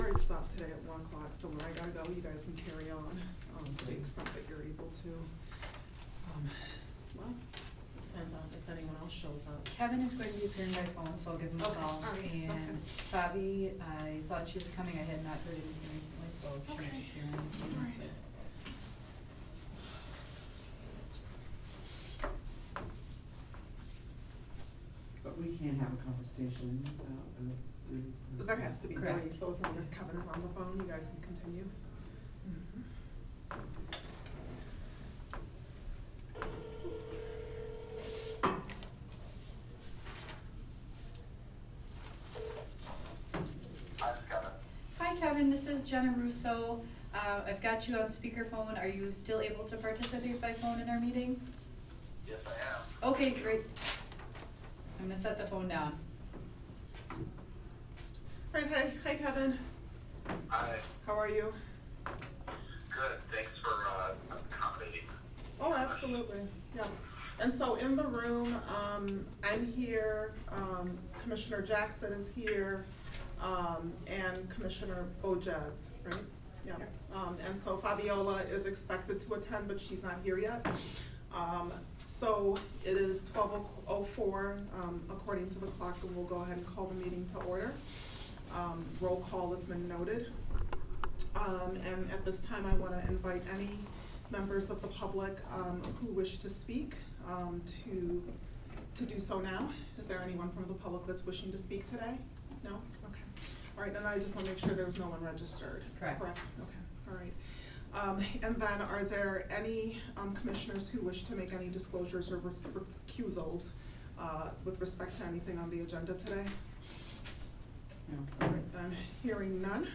Here’s the audio for the meeting at noon yesterday.